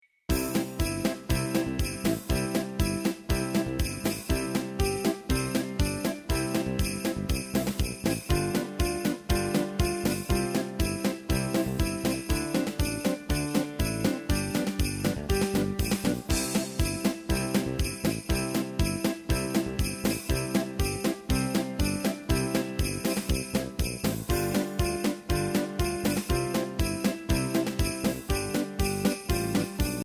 Voicing: Electric Piano